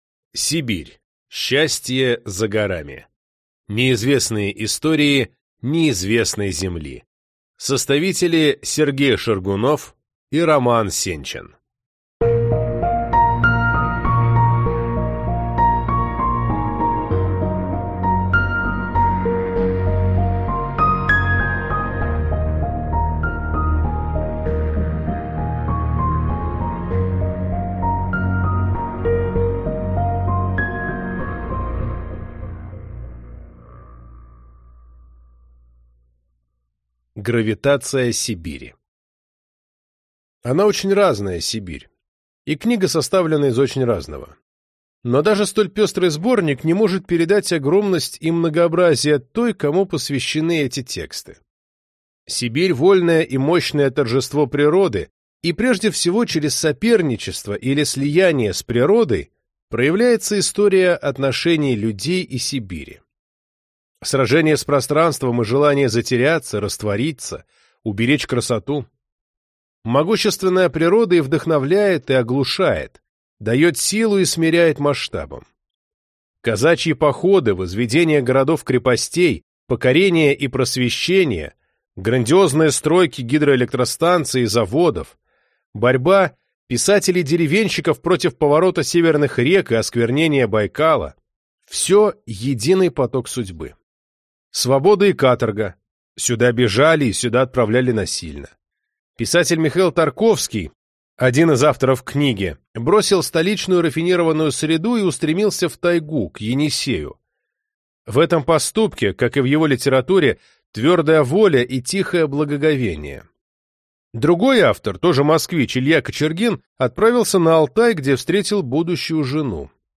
Аудиокнига Сибирь: счастье за горами | Библиотека аудиокниг